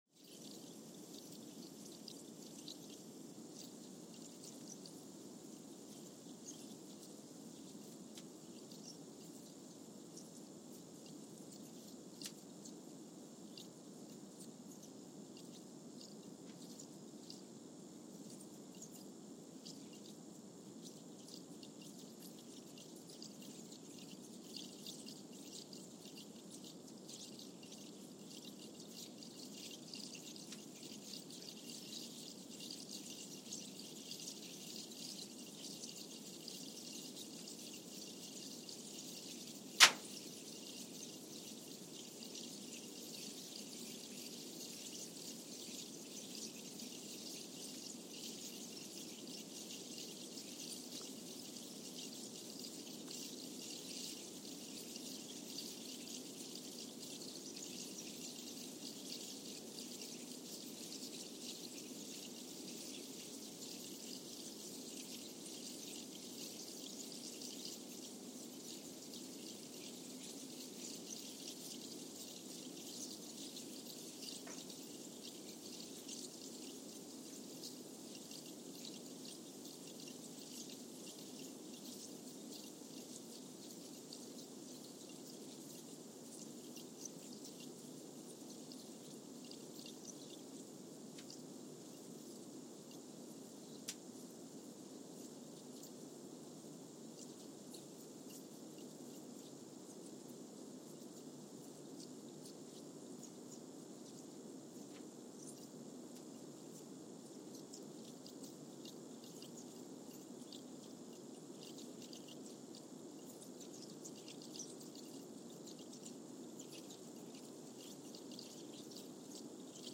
San Juan, Puerto Rico (seismic) archived on January 30, 2023
No events.
Sensor : Trillium 360
Speedup : ×1,000 (transposed up about 10 octaves)
Loop duration (audio) : 05:45 (stereo)
Gain correction : 25dB
SoX post-processing : highpass -2 90 highpass -2 90